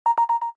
Системные звуки Apple iMac и MacBook Pro и Air в mp3 формате
5. Передача файлов по airdrop
imac-airdrop1.mp3